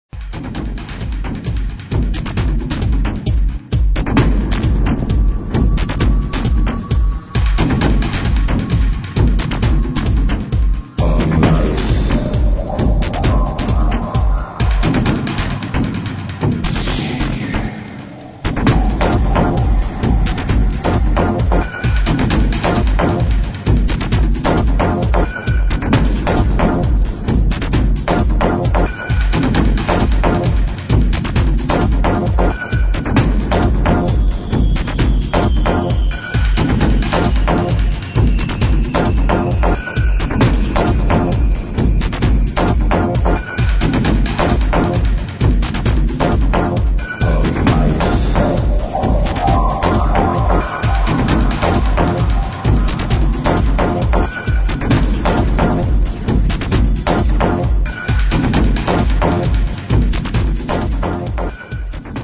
Unknown prog track II